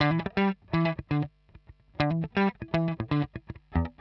120_Guitar_funky_riff_E_3.wav